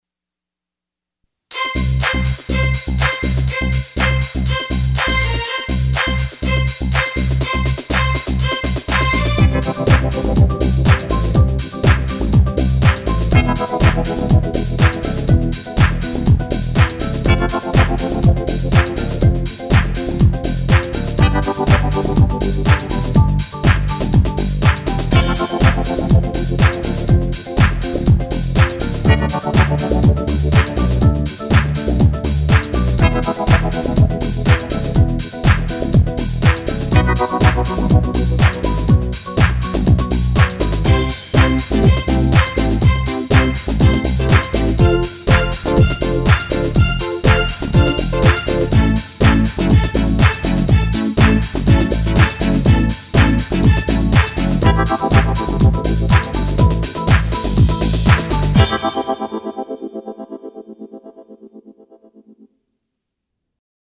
like the days of disco